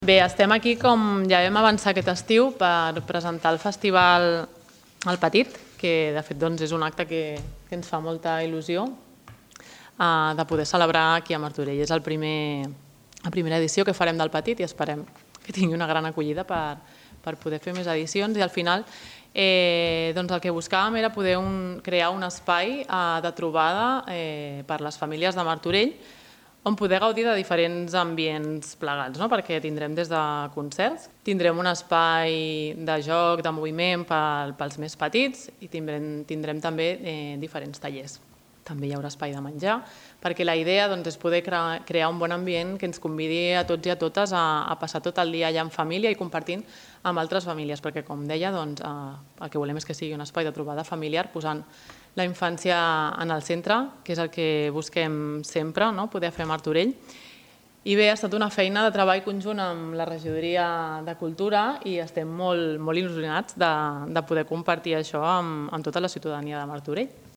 Presentació El Petit
Belén Leiva, regidora d'Infància i Adolescència